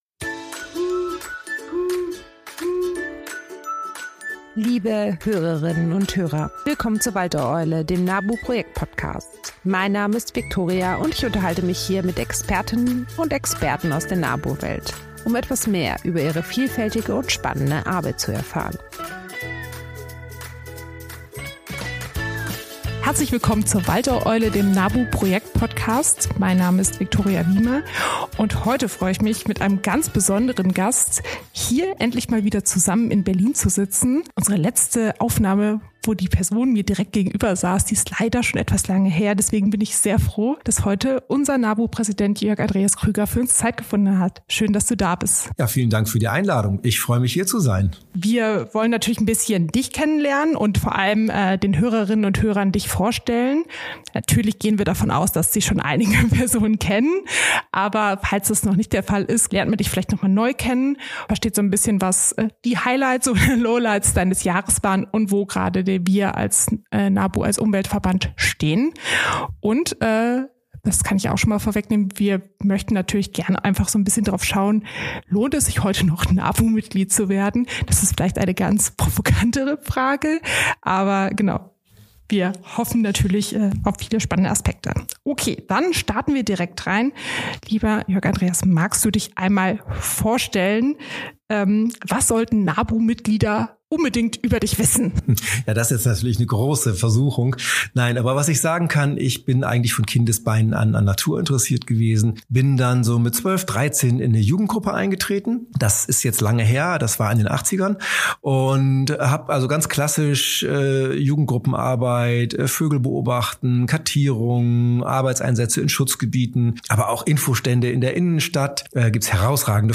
Naturschutz aktiv gestalten - Ein Gespräch mit NABU-Präsident Jörg-Andreas Krüger ~ Waldohreule - NABU Projekt-Podcast Podcast